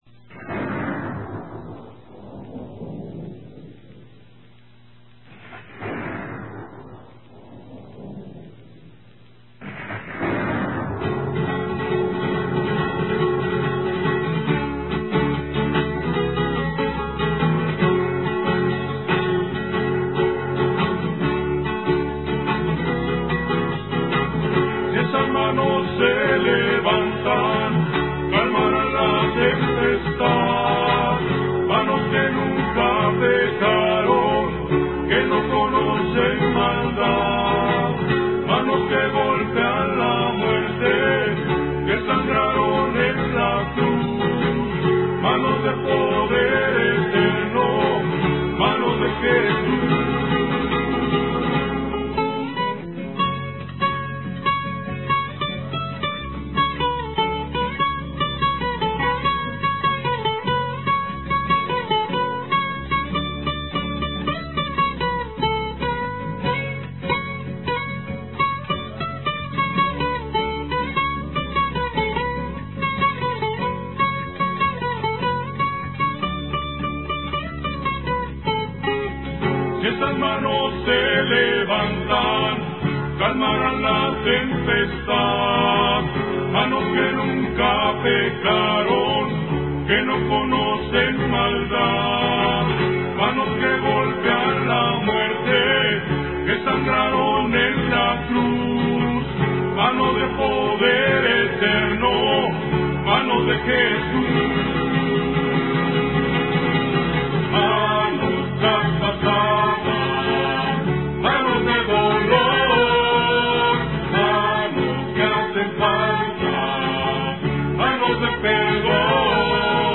(Contralto)
(Tenor)